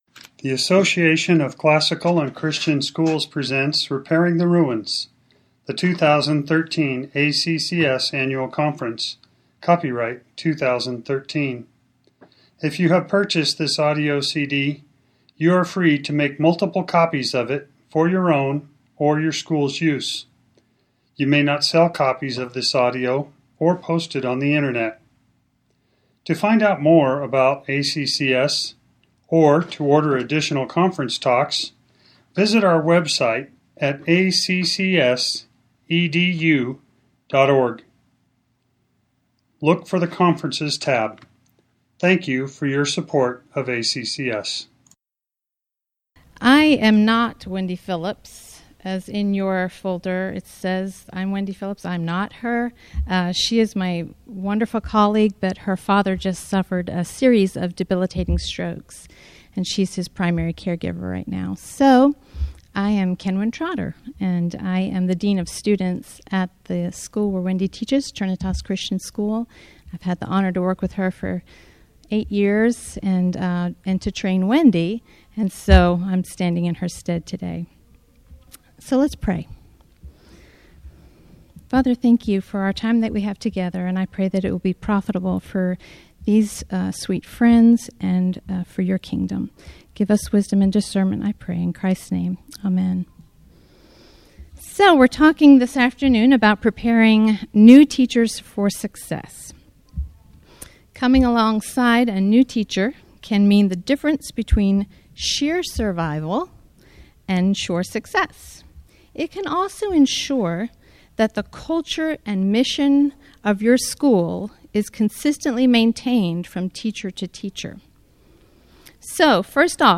2013 Workshop Talk | 0:55:56 | Leadership & Strategic, Training & Certification